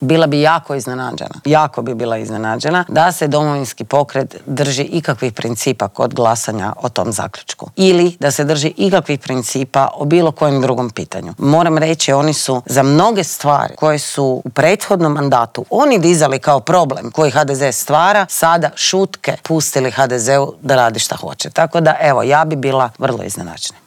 O političkim aktualnostima, nadolazećim lokalnim izborima te o aktualnoj geopolitičkoj situaciji u svijetu razgovarali smo u Intervjuu tjedna Media servisa sa saborskom zastupnicom i koordinatoricom stranke Možemo! Sandrom Benčić.